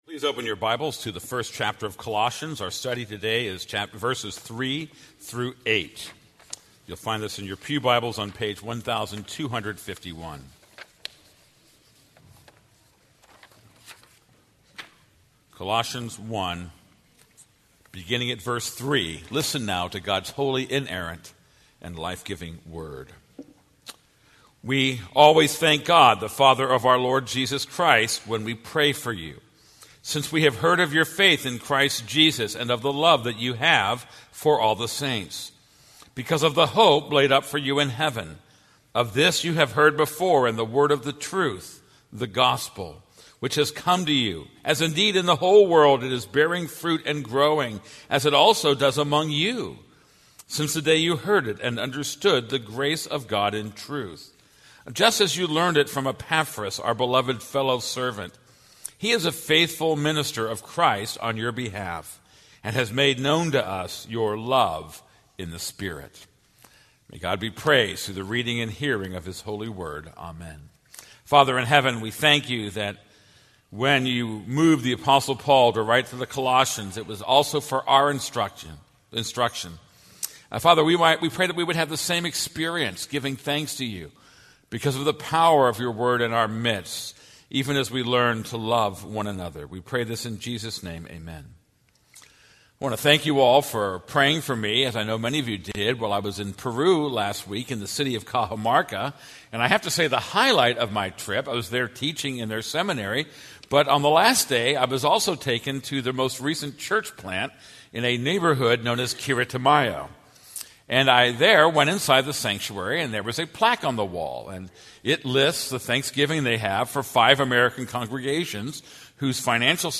This is a sermon on Colossians 1:3-8.